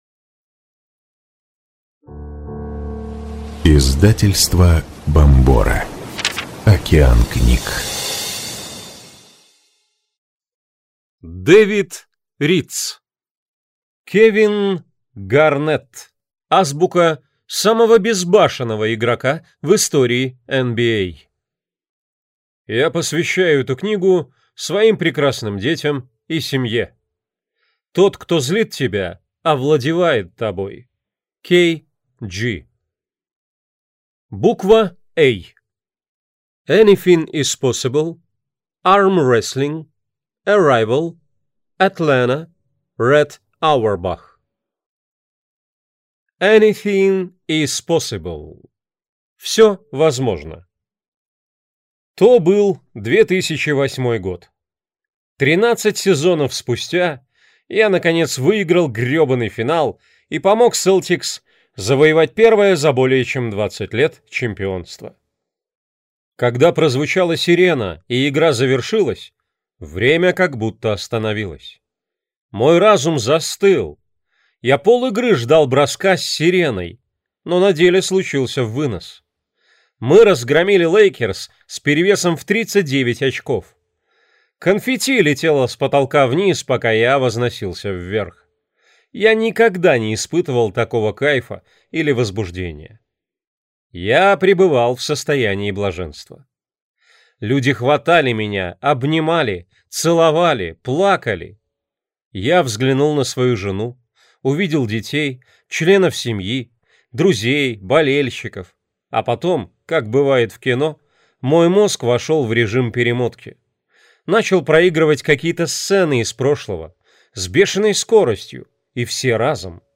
Аудиокнига Кевин Гарнетт. Азбука самого безбашенного игрока в истории НБА | Библиотека аудиокниг